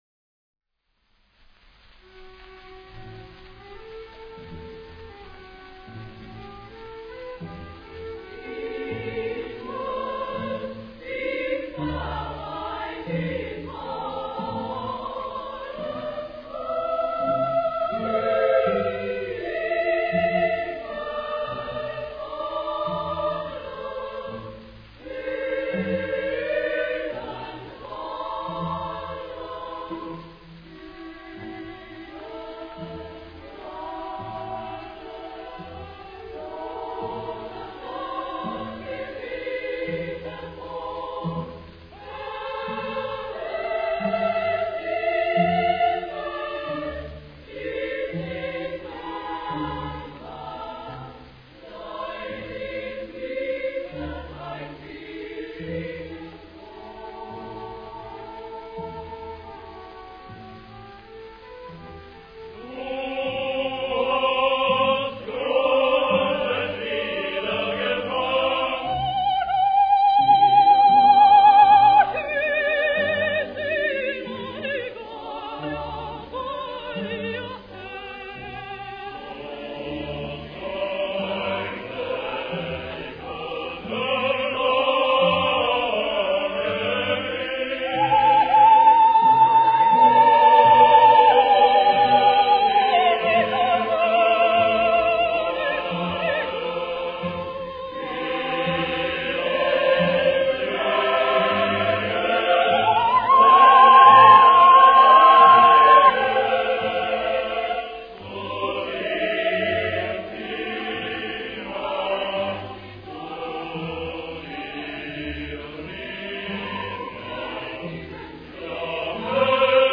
The cantata from Puccini's Tosca (Sung in German.) Listen to the way Seinemeyer's voice soars above the chorus.